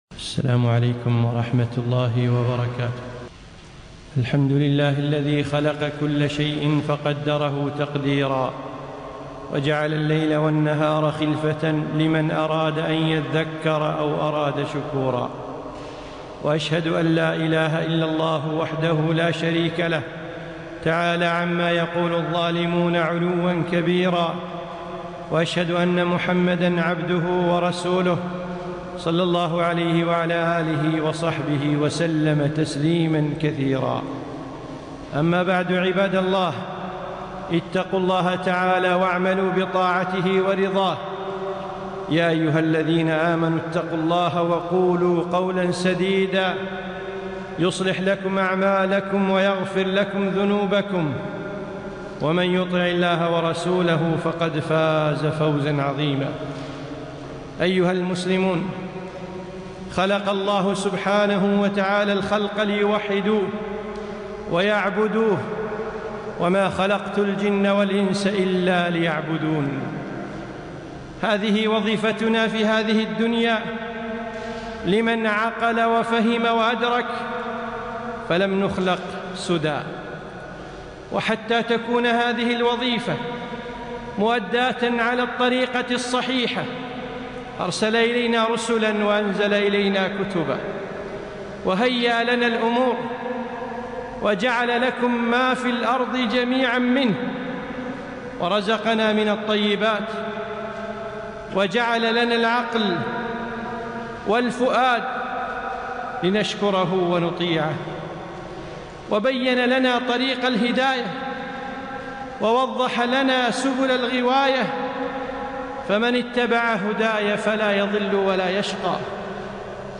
خطبة - اليوم زرع وغدا حصاد